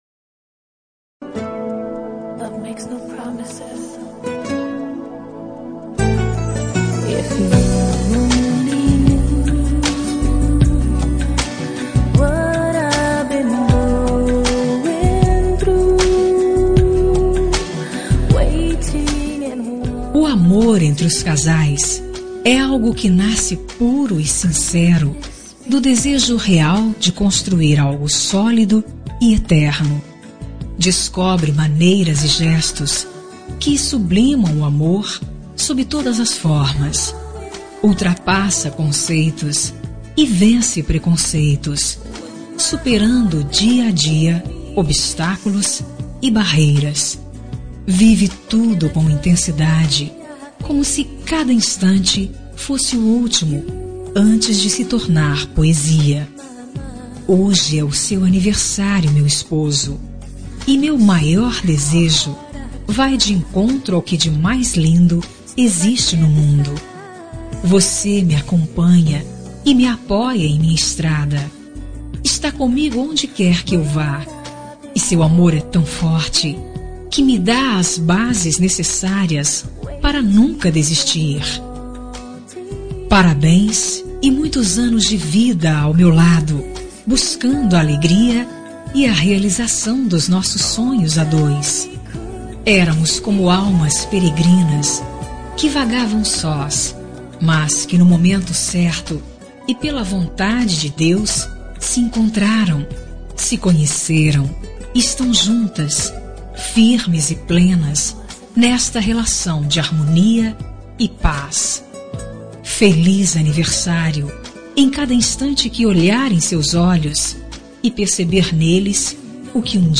Telemensagem de Aniversário de Marido – Voz Feminina – Cód: 1153